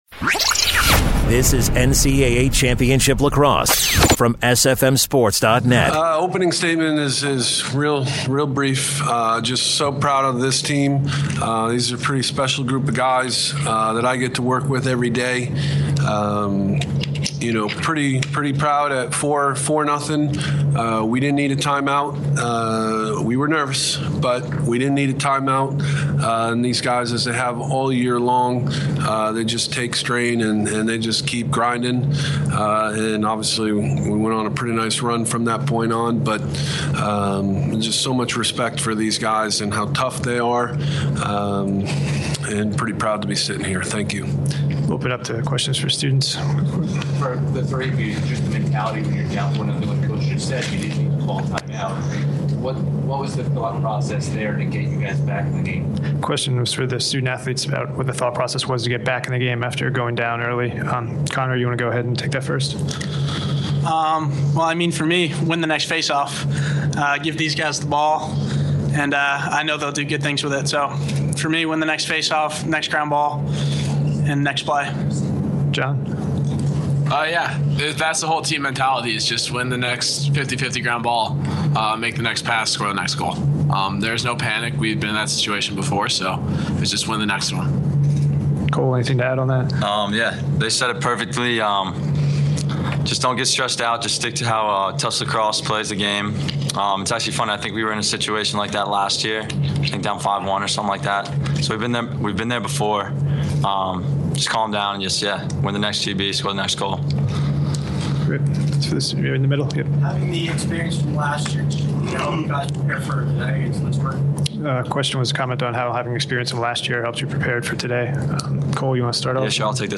Tufts Jumbos Post-Game Press Conference